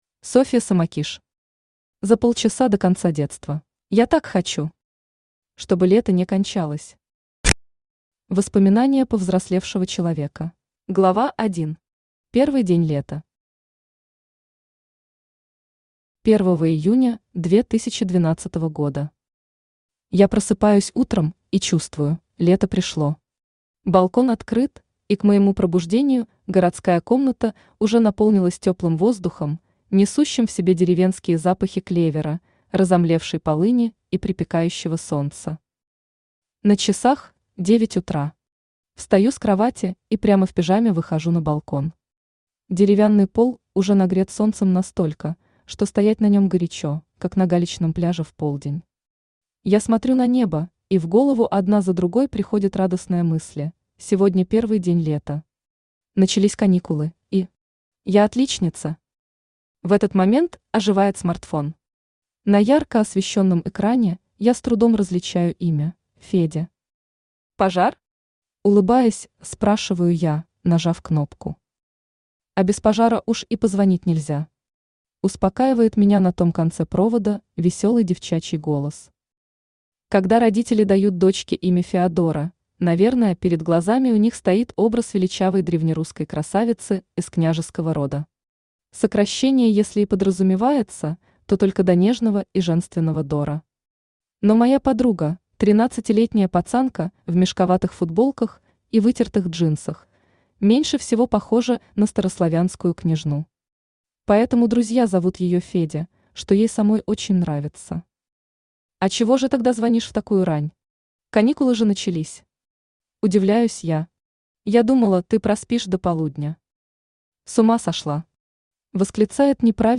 Аудиокнига За полчаса до конца детства | Библиотека аудиокниг
Aудиокнига За полчаса до конца детства Автор Софья Самокиш Читает аудиокнигу Авточтец ЛитРес.